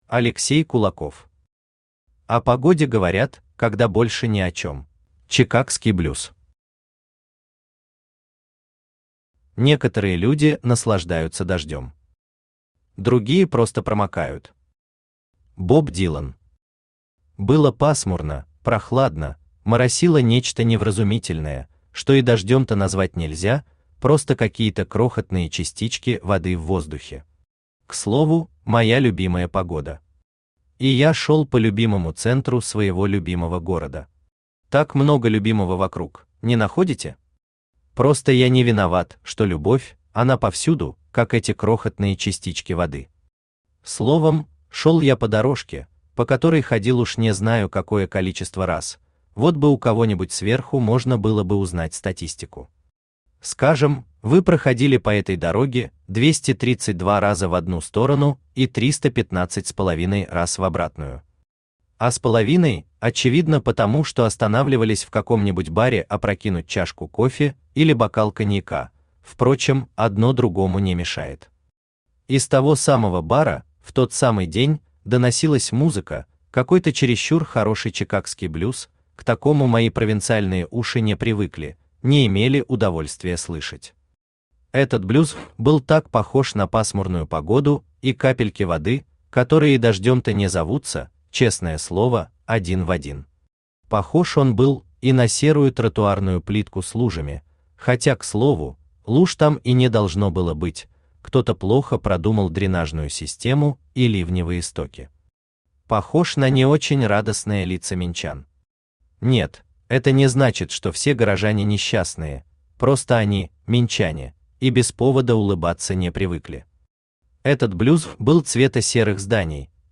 Aудиокнига О погоде говорят, когда больше не о чем Автор Алексей Олегович Кулаков Читает аудиокнигу Авточтец ЛитРес.